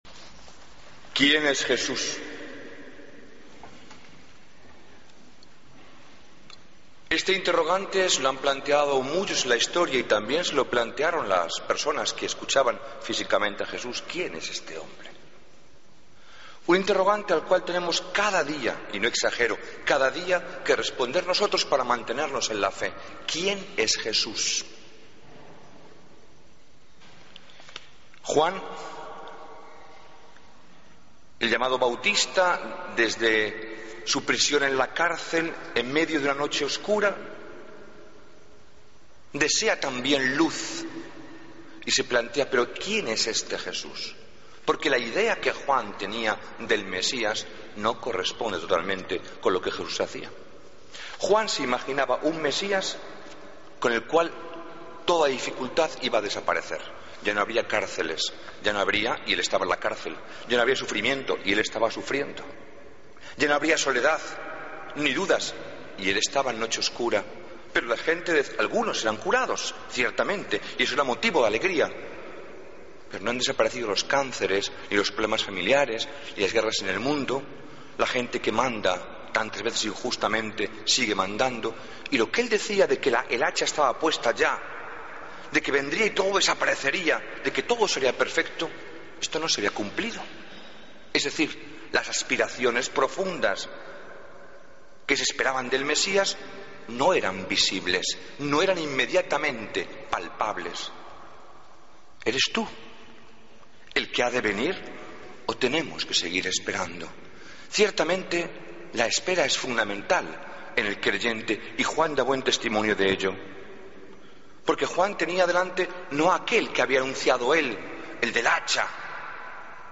Homilía del Domingo 15 de Diciembre de 2013